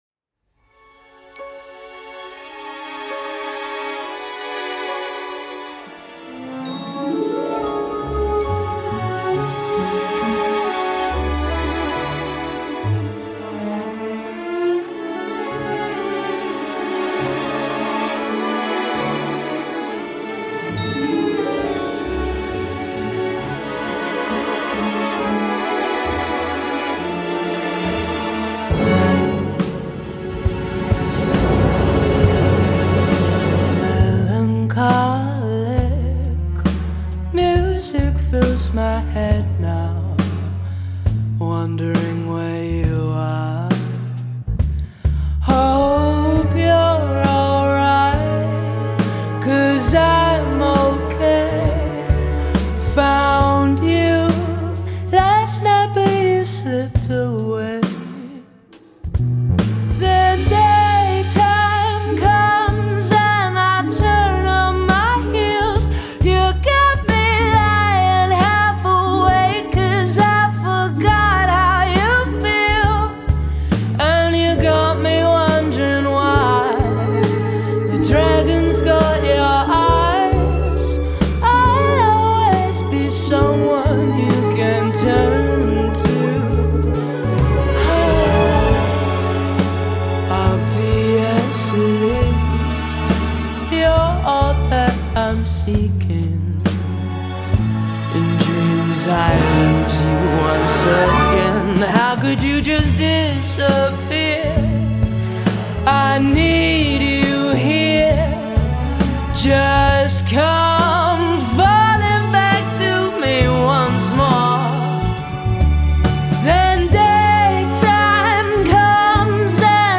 Recorded at: Abbey Road Studios – March 2025
blend neo-soul, jazz, pop, and electronic music
soulful and dynamic sound